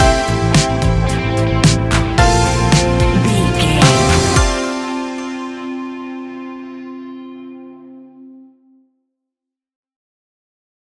Ionian/Major
ambient
electronic
new age
downtempo
pads